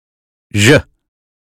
us_phonetics_sound_vision_2023feb.mp3